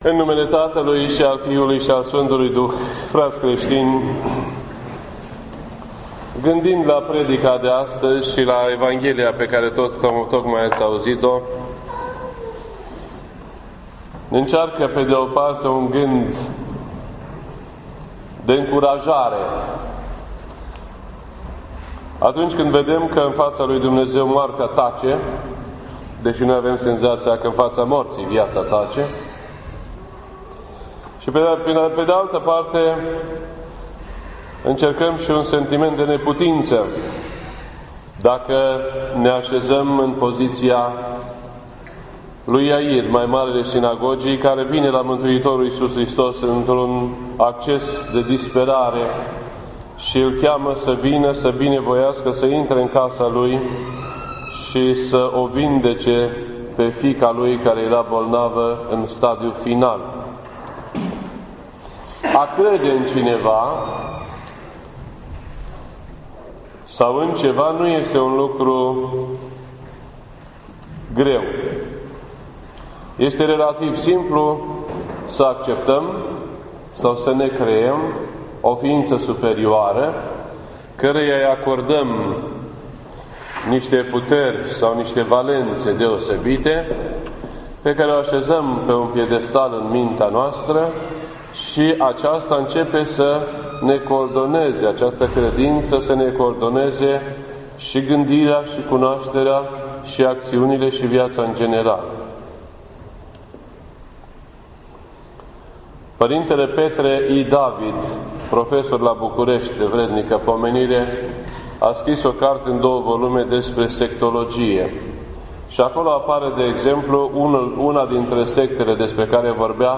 This entry was posted on Sunday, November 6th, 2016 at 10:26 AM and is filed under Predici ortodoxe in format audio.